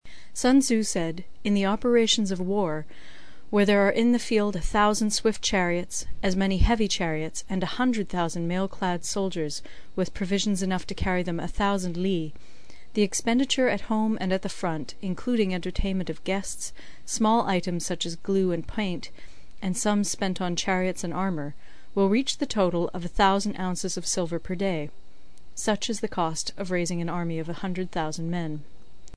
有声读物《孙子兵法》第8期:第二章 作战(1) 听力文件下载—在线英语听力室